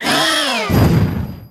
Cri de Matoufeu dans Pokémon Soleil et Lune.